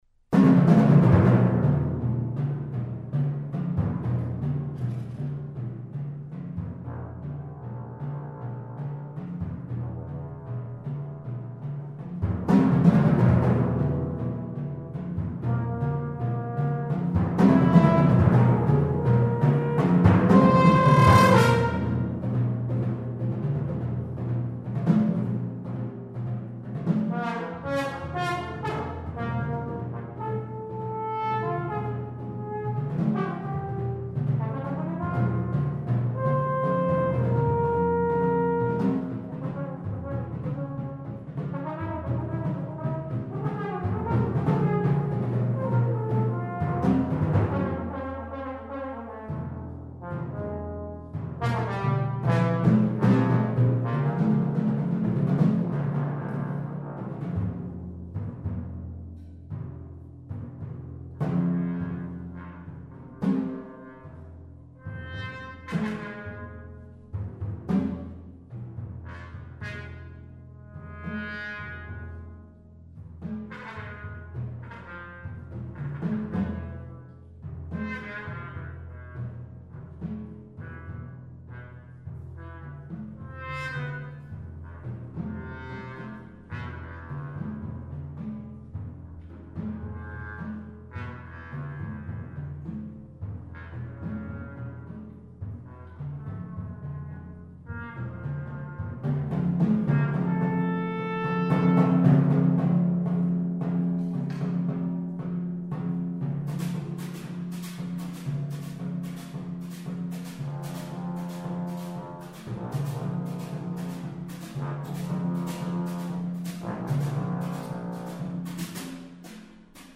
Voicing: Chamber Duet